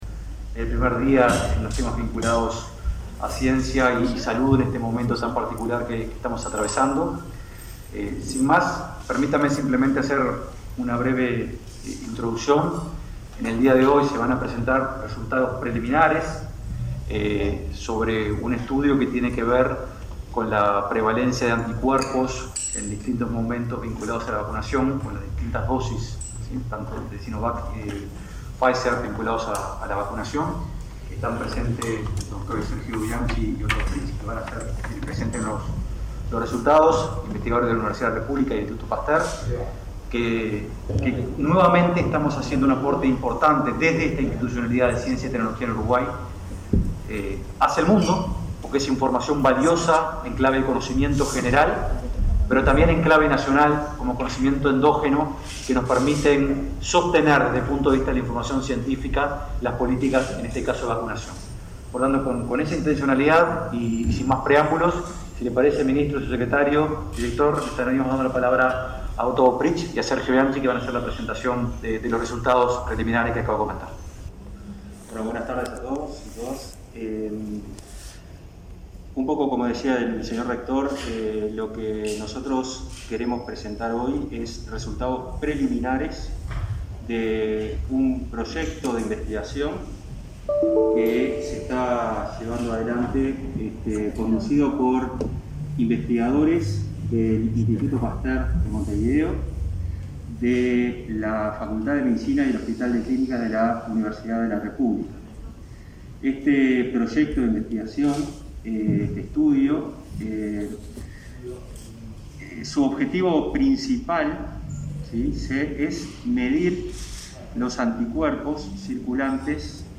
Conferencia de prensa por la presentación de datos de la investigación sobre efectividad de vacunas contra COVID-19